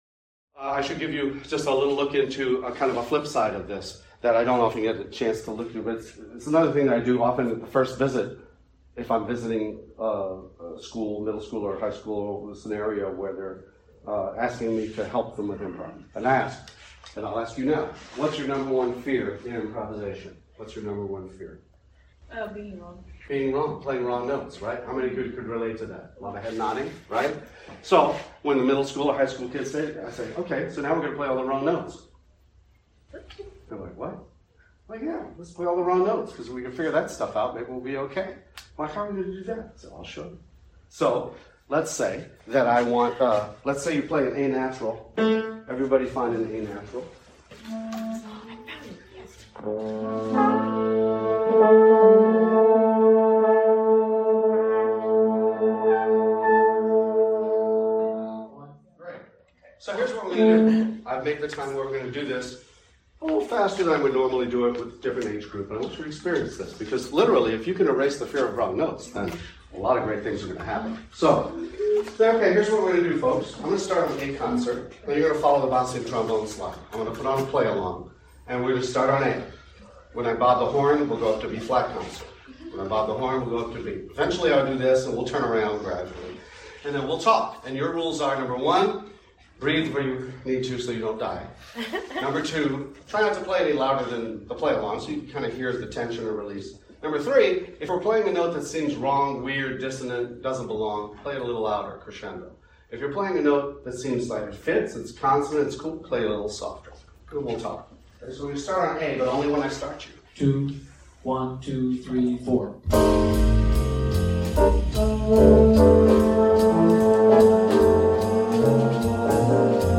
Hear a 12-minute audio recording where I teach this concept to music educators using several of the notated examples below! Young improvisers fear mistakes, the accidental dissonances as they solo.